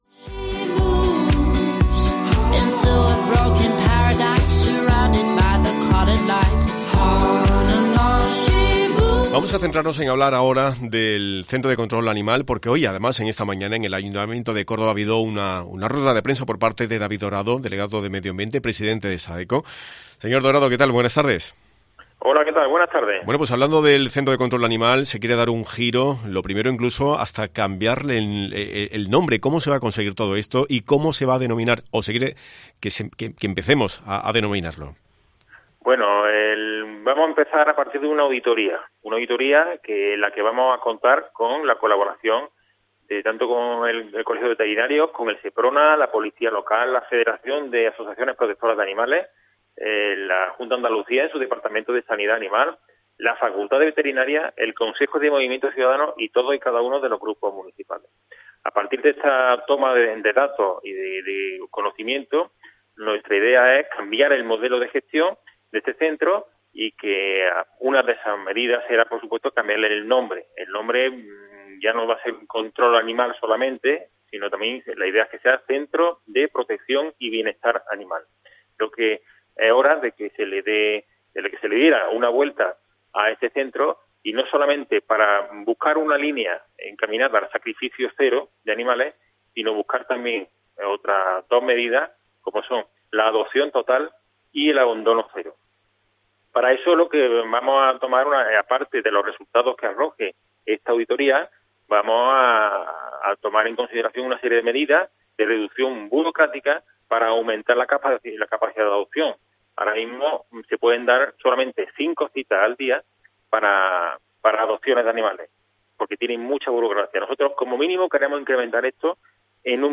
David Dorado, presidente de Sadeco sobre el Centro de Control de Animal de Córdoba